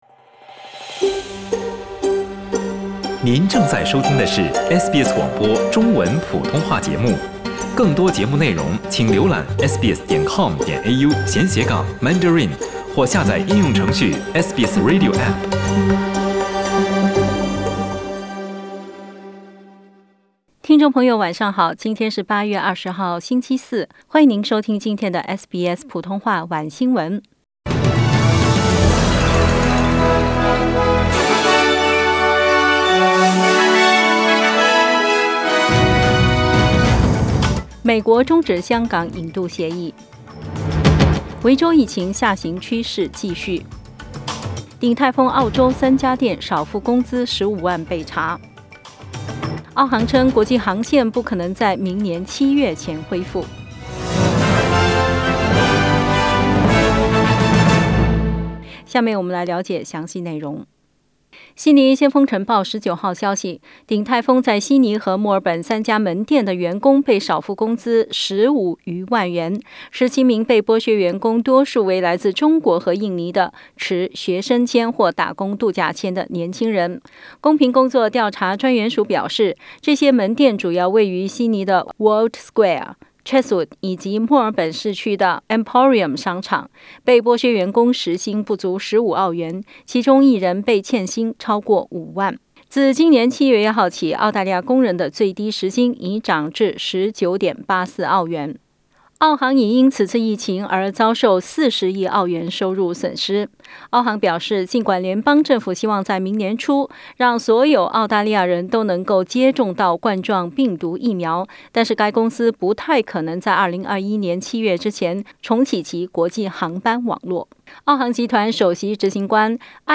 SBS晚新闻（8月20日）